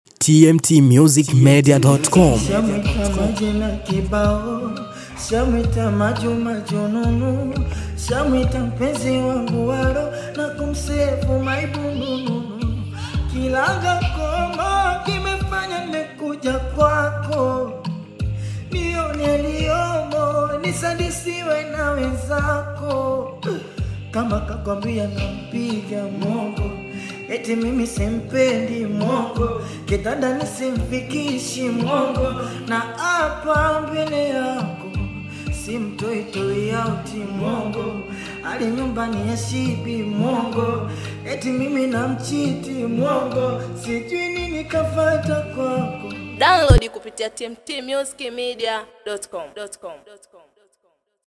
BONGO FLAVOUR